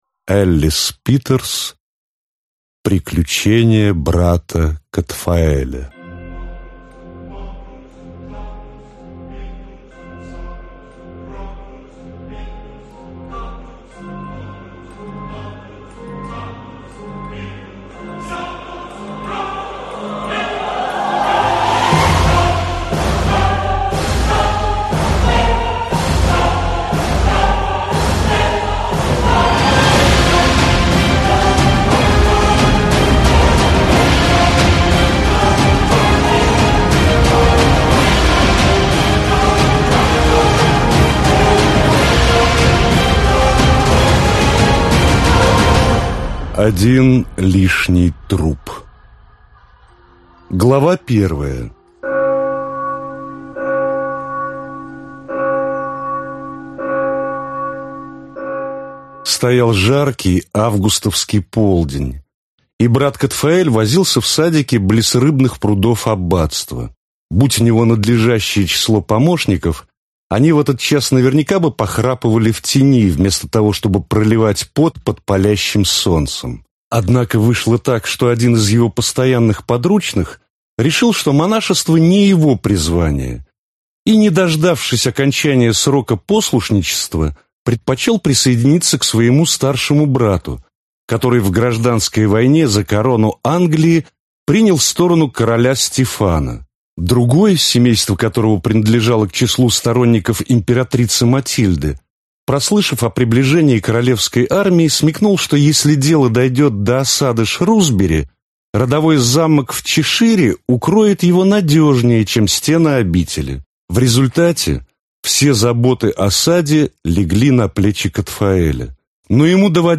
Аудиокнига Один лишний труп | Библиотека аудиокниг